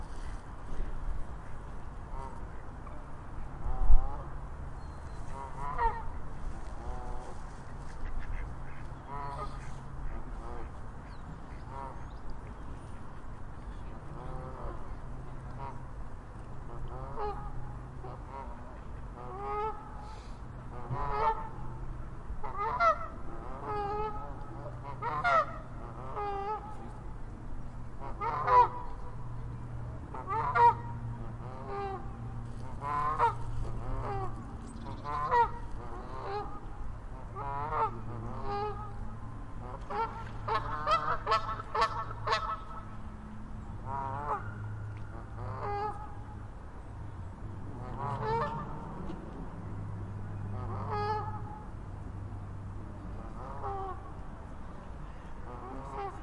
加拿大鹅1
描述：加拿大鹅飞行和降落在湖岸附近。用奥林巴斯LS14录制。
标签： 性质 现场记录 鸟类 鸣喇叭
声道立体声